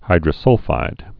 (hīdrə-sŭlfīd)